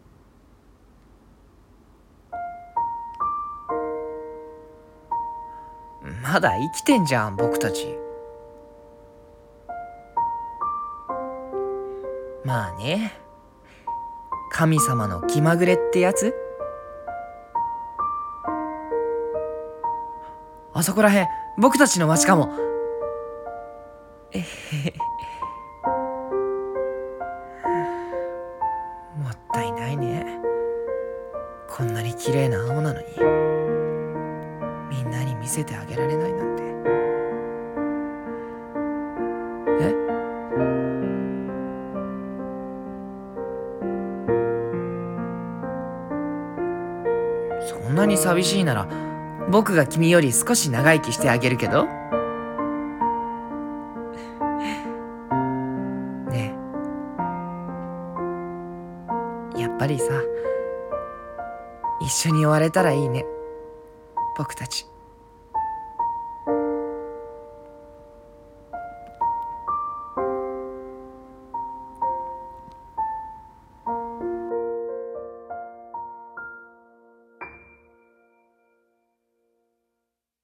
二人声劇